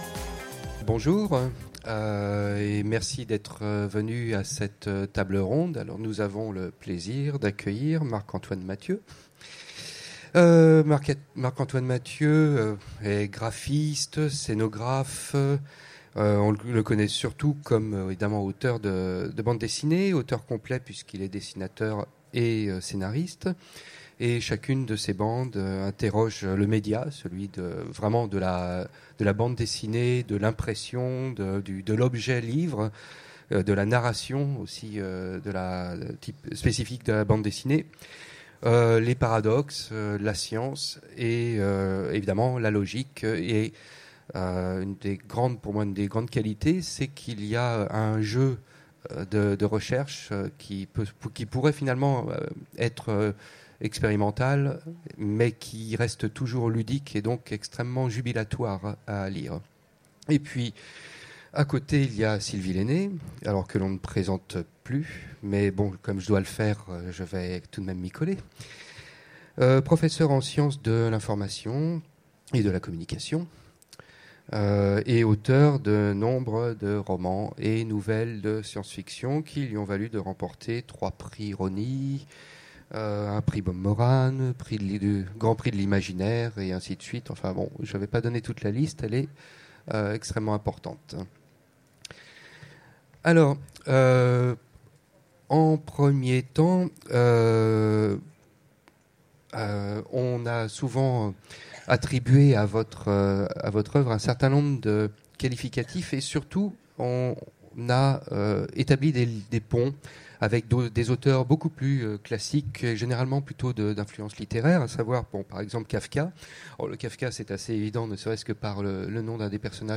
Utopiales 2015 : Rencontre avec Marc-Antoine Mathieu
Utopiales 2015 : Rencontre avec Marc-Antoine Mathieu Télécharger le MP3 à lire aussi Sylvie Lainé Marc-Antoine Mathieu Genres / Mots-clés bande dessinée Rencontre avec un auteur Conférence Partager cet article